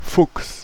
De-Fuchs.ogg.mp3